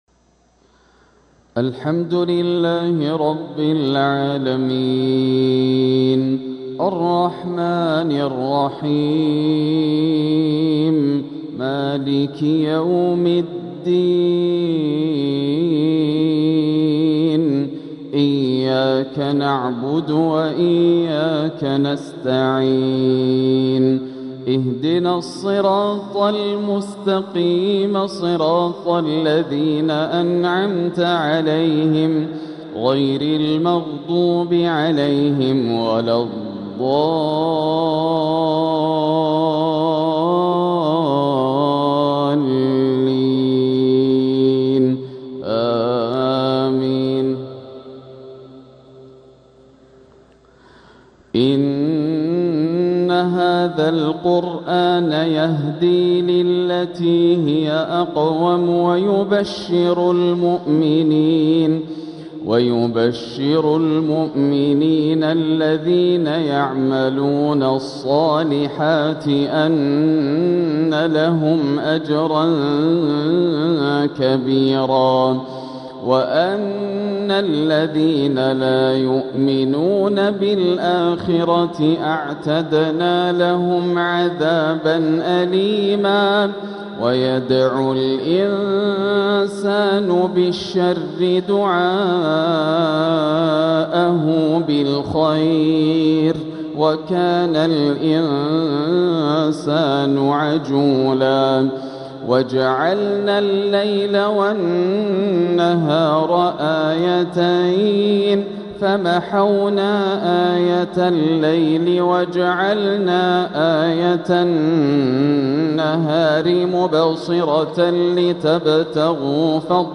عشاء الجمعة 7 صفر 1447هـ من سورة الإسراء 9-24 | lsha prayer from Surah Al-Israa 1-8-2025 > 1447 🕋 > الفروض - تلاوات الحرمين